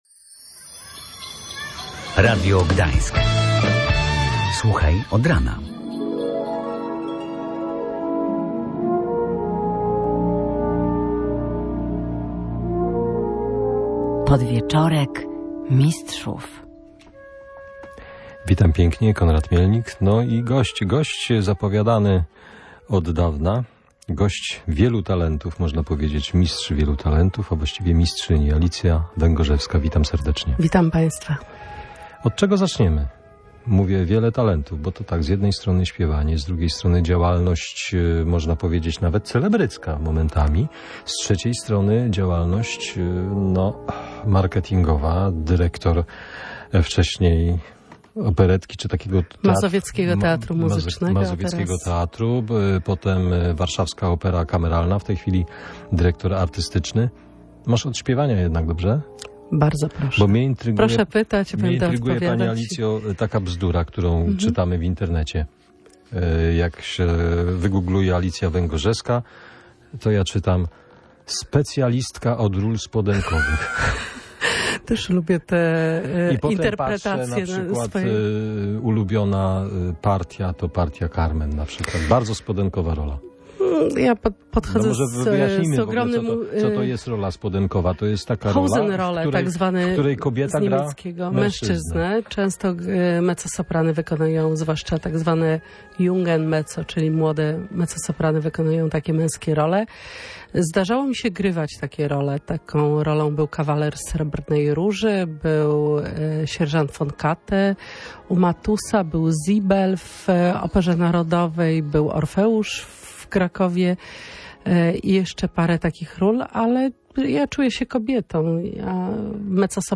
Audycje muzyczne